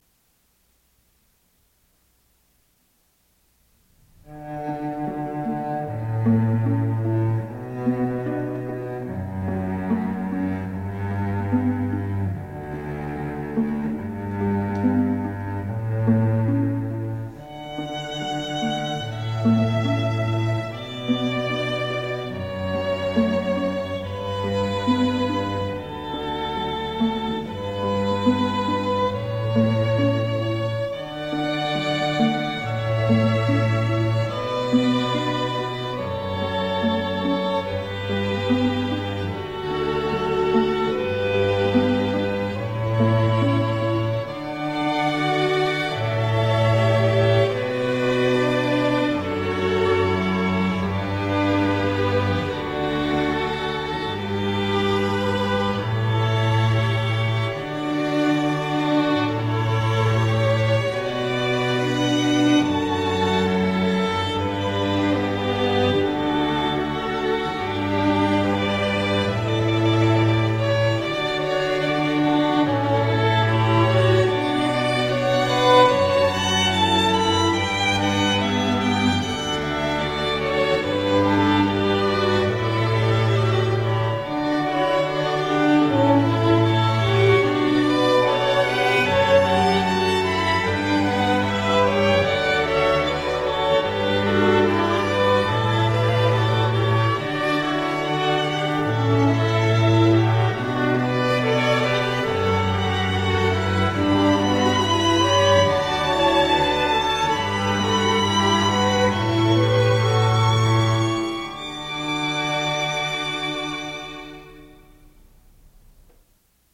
ELEGANT STRING MUSIC FOR ANY OCCASION